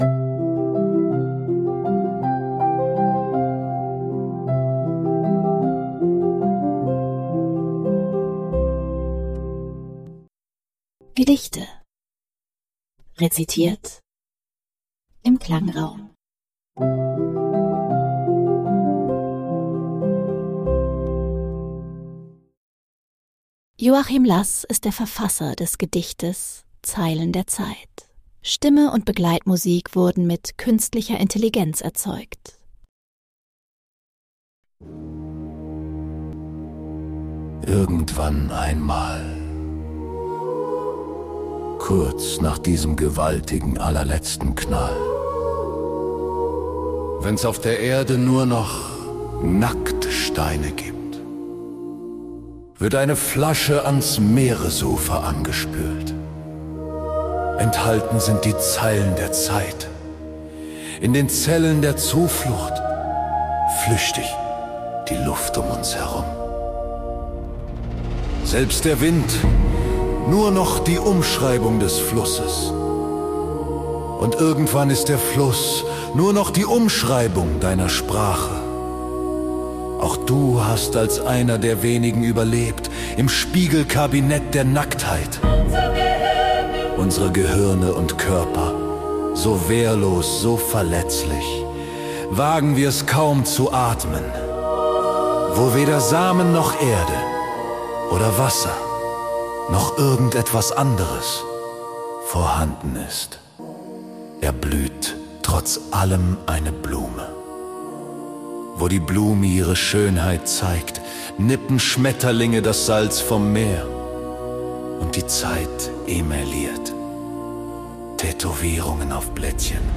Stimme und Begleitmusik wurden mithilfe Künstlicher